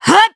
Valance-Vox_Attack2_jp.wav